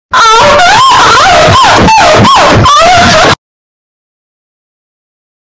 Funny Guy saying fortnite